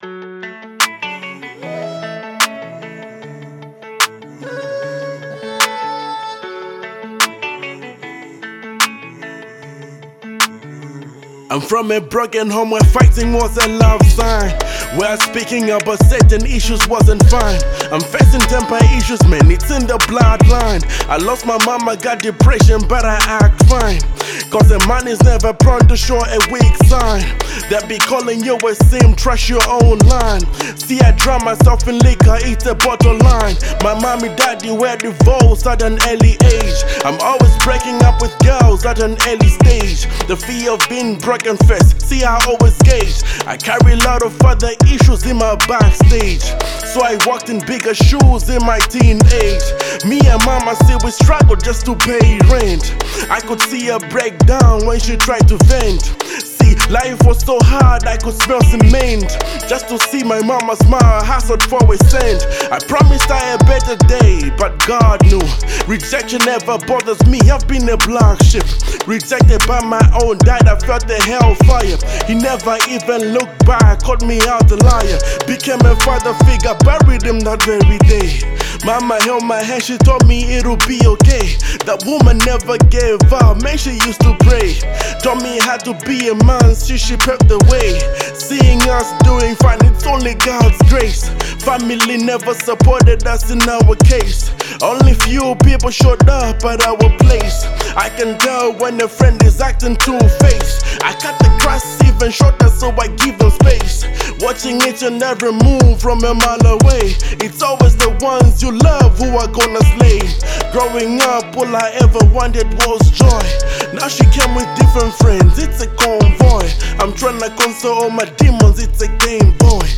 Zambian based award-winning Gospel music artiste